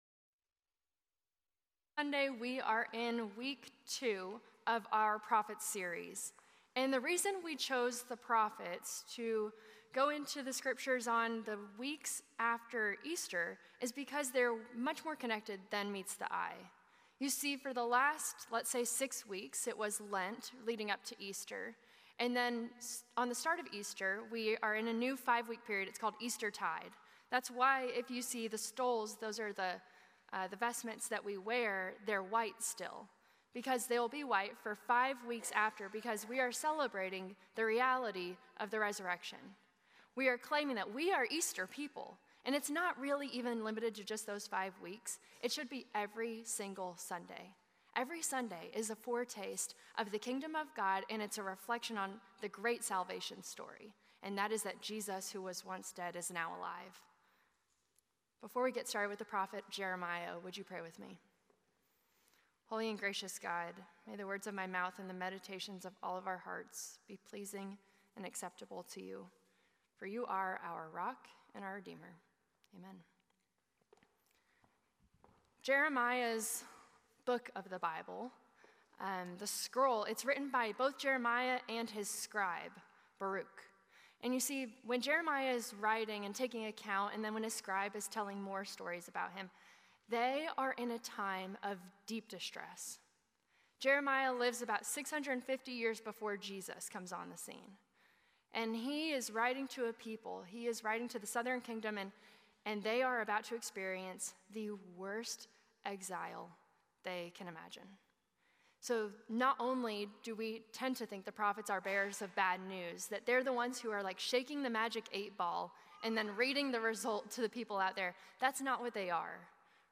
A message from the series "Prophets."